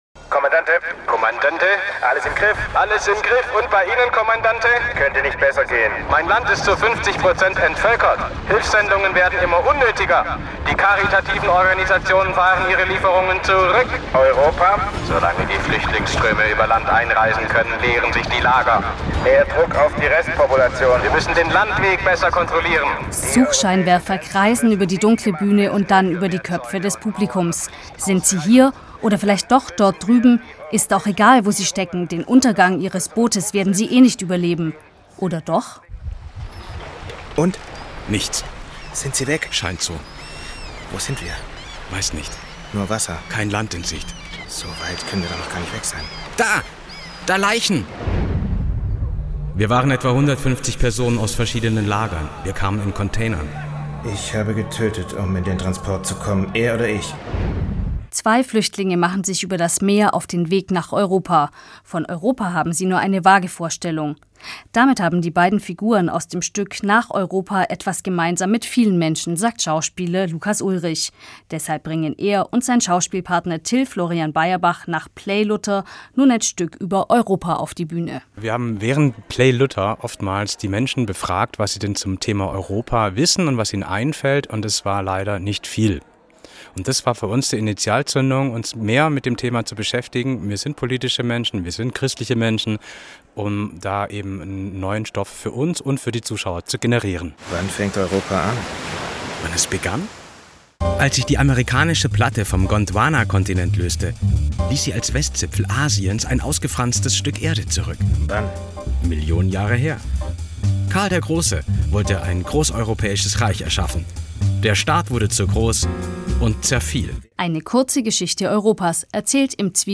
Radiobeitrag zur Premiere auf SWR1-Sonntagmorgen